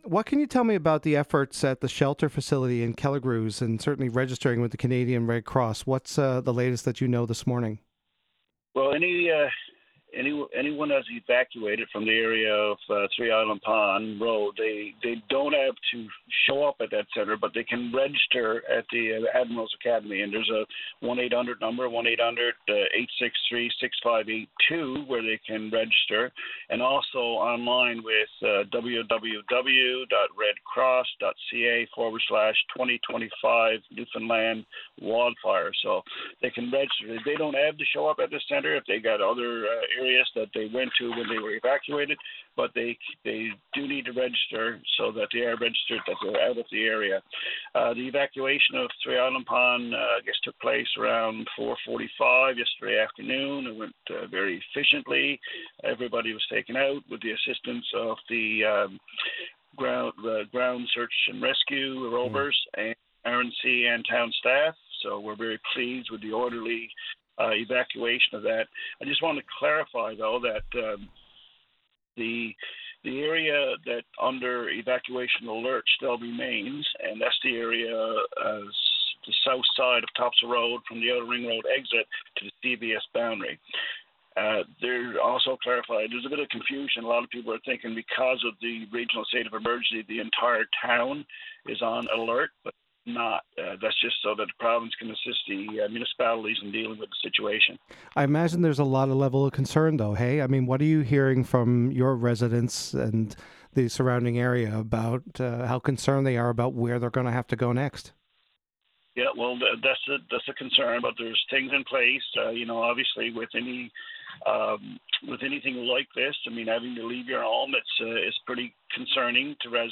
LISTEN: Paradise Mayor Dan Bobbett Provides Update on Your VOCM Mornings – August 13, 2025